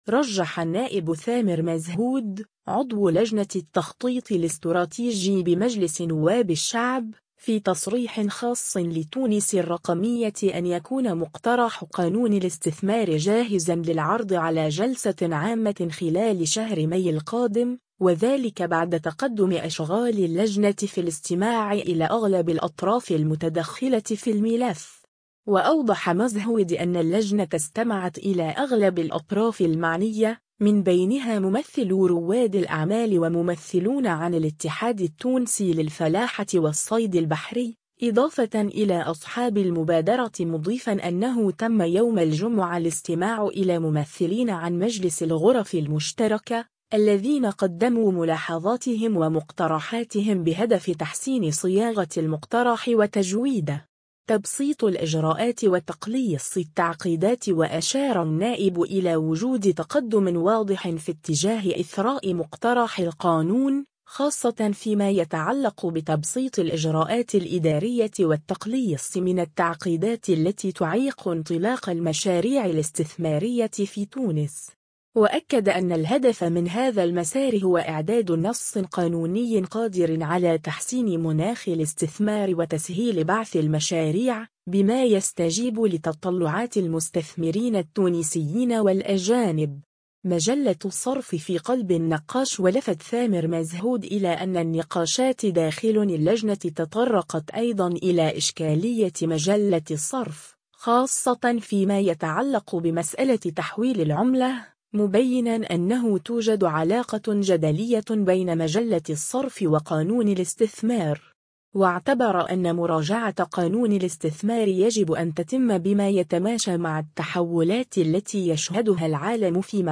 رجّح النائب ثامر مزهود، عضو لجنة التخطيط الاستراتيجي بمجلس نواب الشعب، في تصريح خاص لـ”تونس الرقمية” أن يكون مقترح قانون الاستثمار جاهزا للعرض على جلسة عامة خلال شهر ماي القادم، وذلك بعد تقدم أشغال اللجنة في الاستماع إلى أغلب الأطراف المتدخلة في الملف.